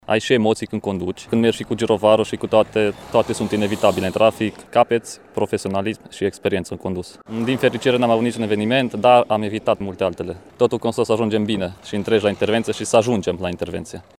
Sergent major